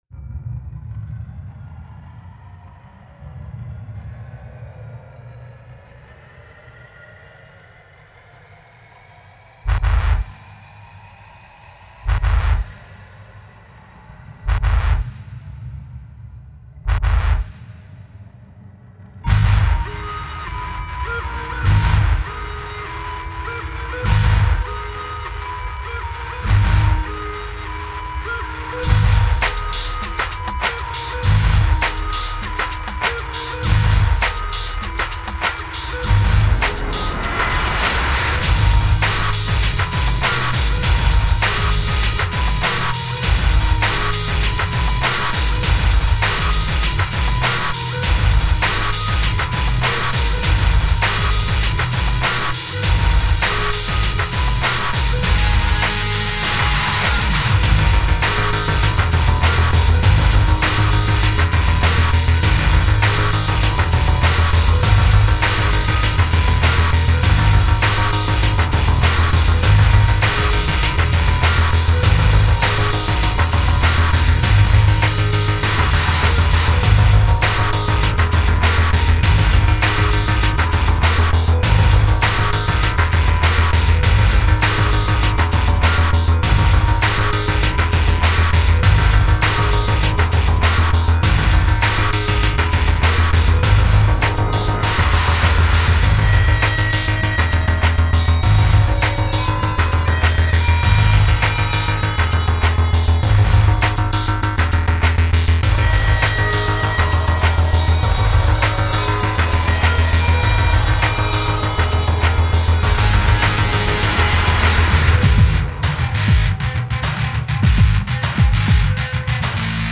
Remixed version